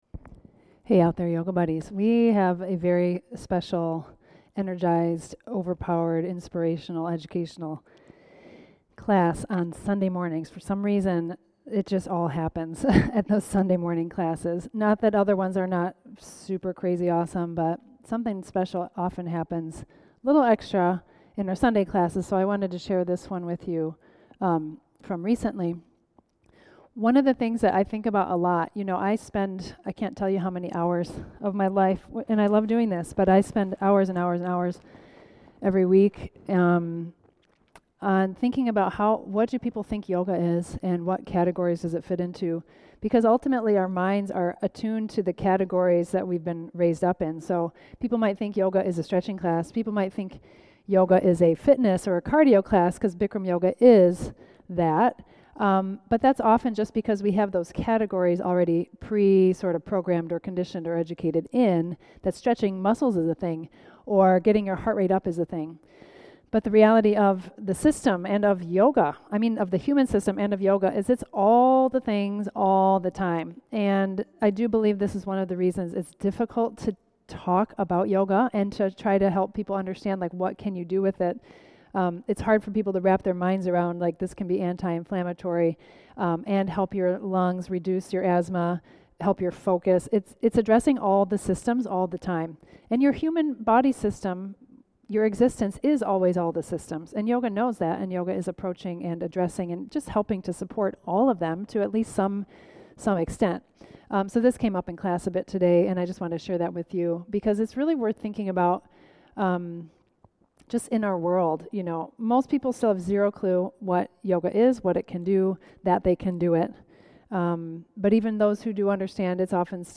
Traditional Bikram Yoga classes (and so much more!) from Bikram Yoga Online.